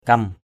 /kʌm/ 1.